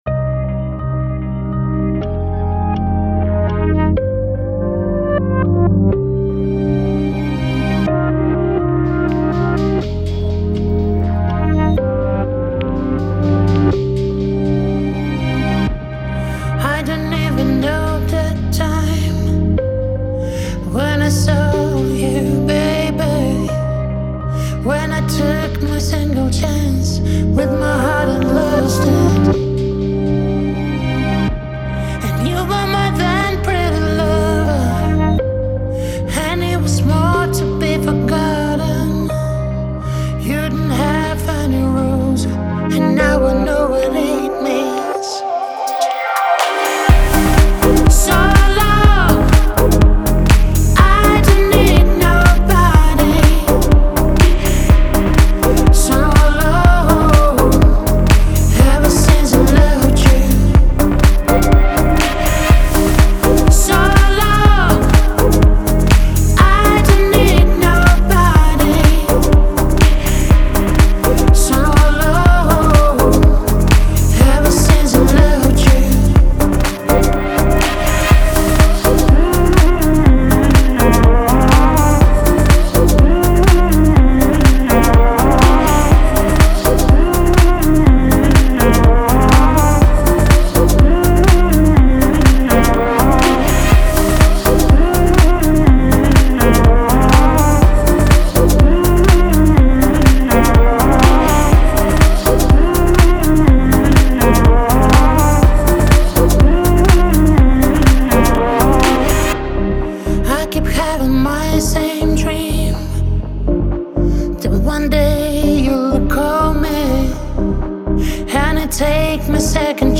Стиль: Deep House / Dance / Pop